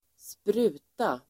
Uttal: [²spr'u:ta]